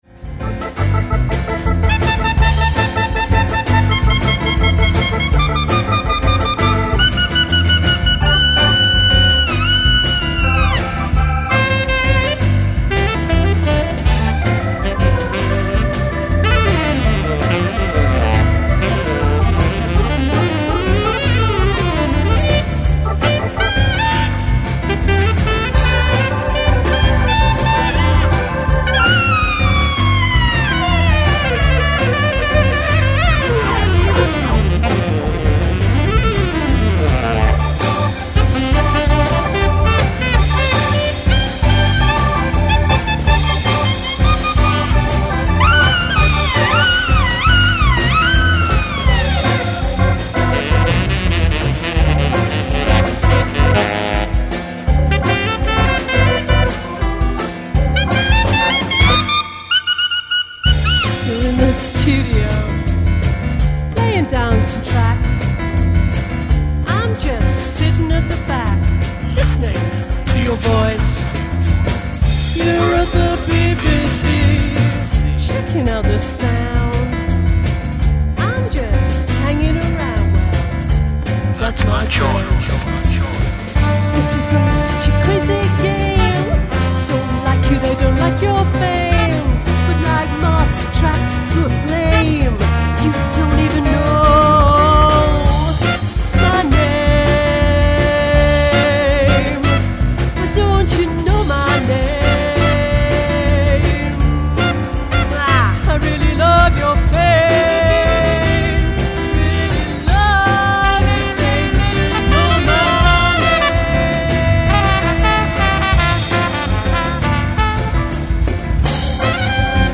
a blend of jazz-infused songwriting & grooves
jazz-fusion group